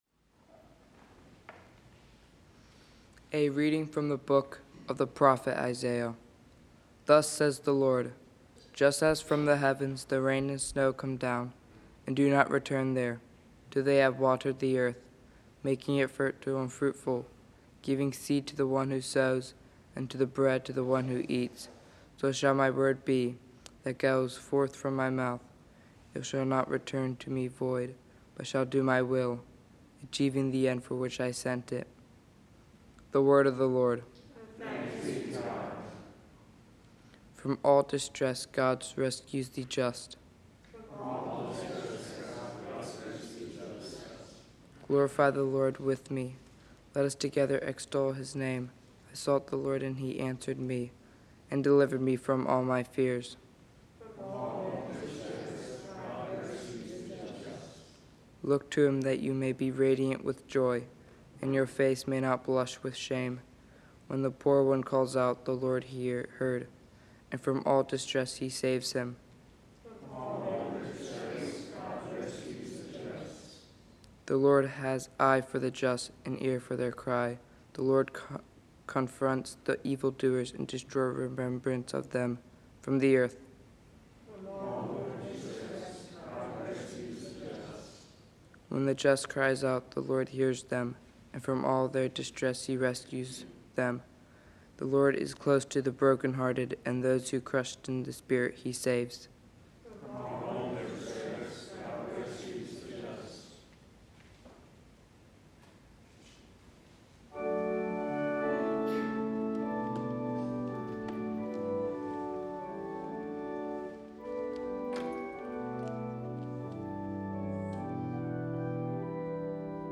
1 Readings and homily